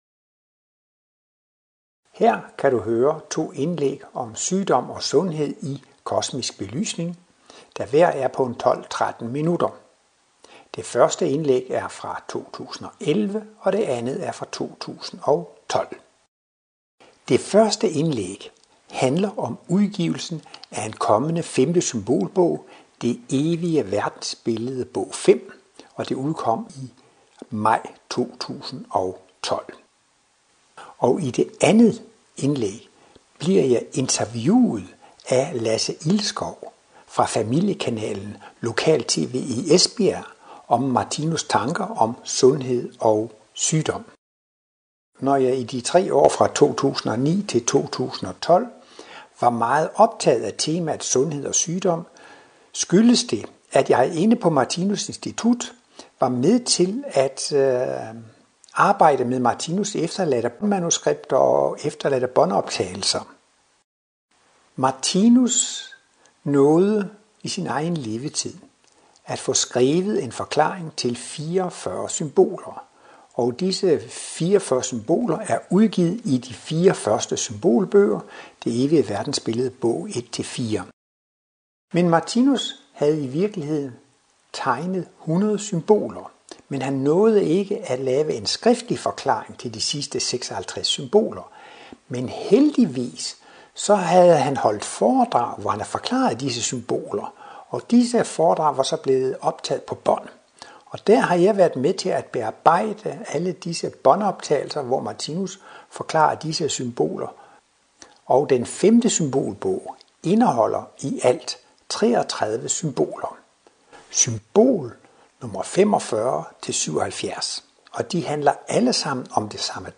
Foredrag